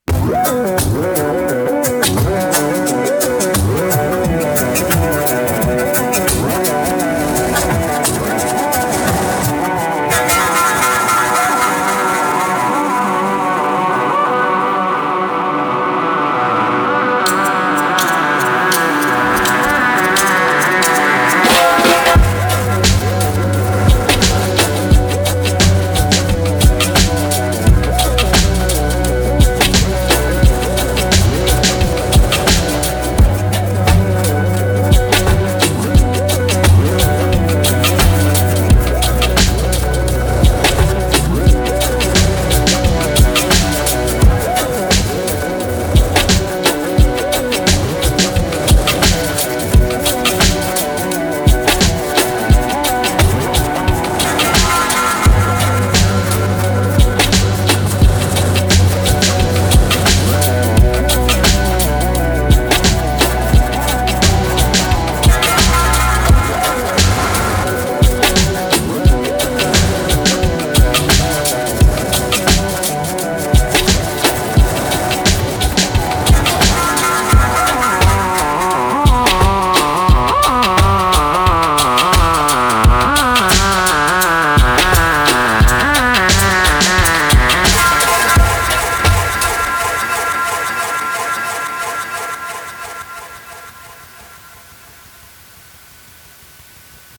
Last month I did this kinda acid dub thing, if that’s a thing at all.